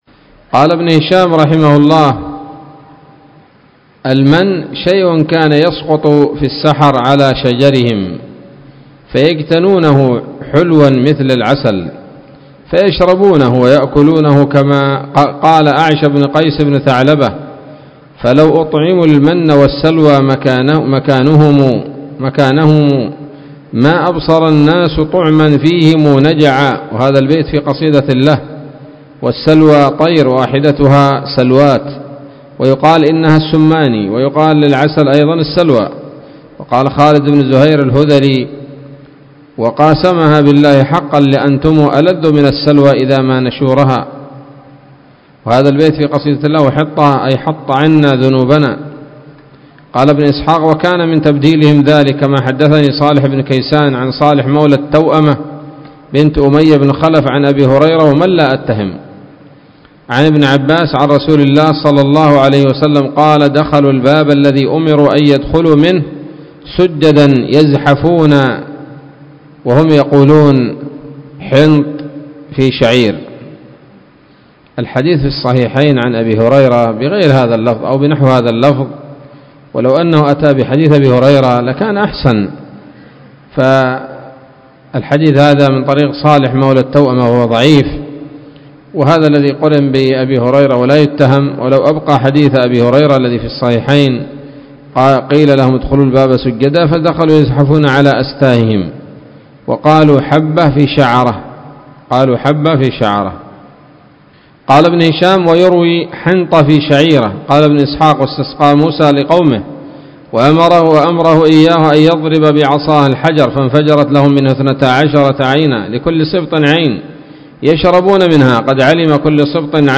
الدرس التاسع والثمانون من التعليق على كتاب السيرة النبوية لابن هشام